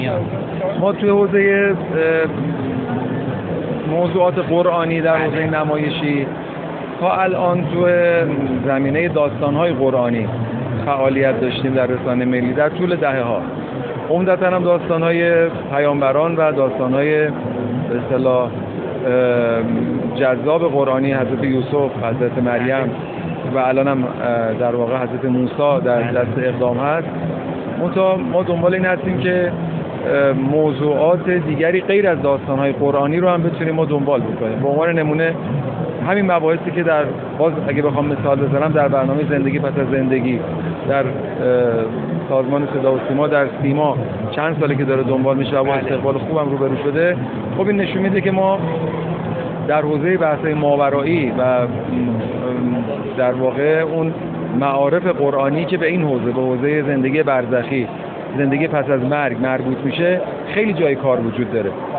جبلی در گفت‌وگو با ایکنا: